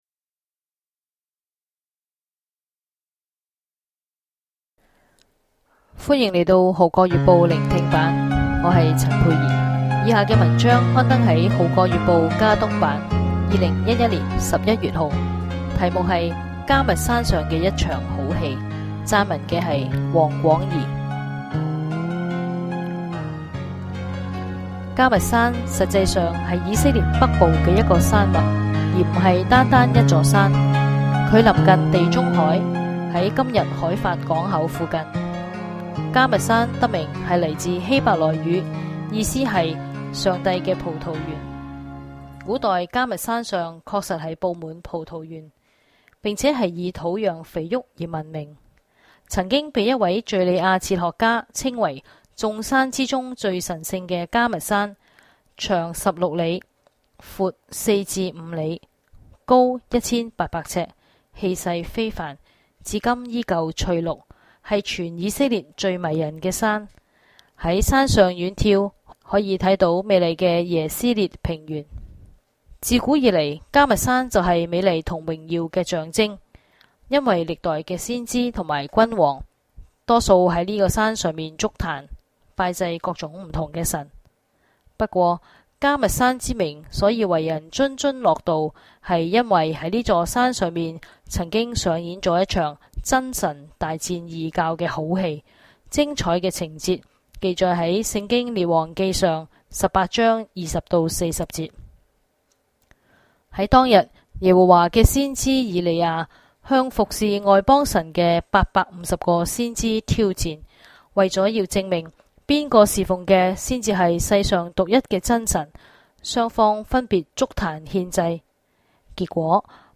聆聽版/Audio迦密山上的一場好戲 向聖地出發